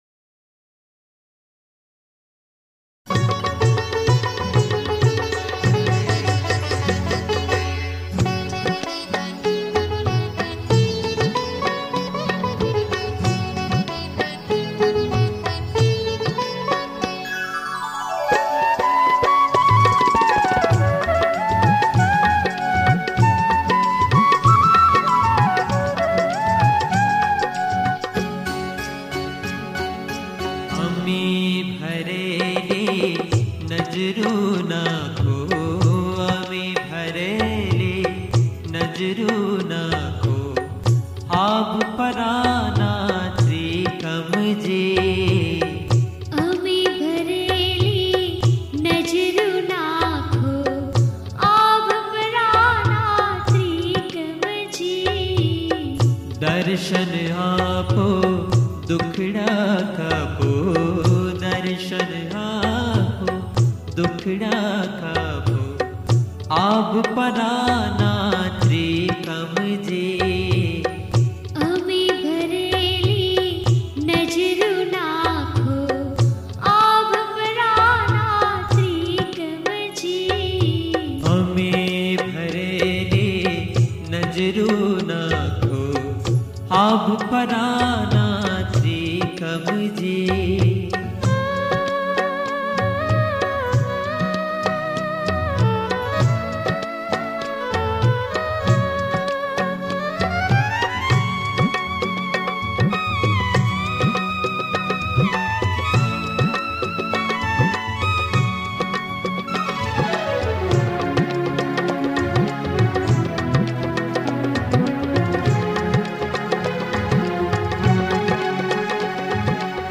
(Prabhatiya)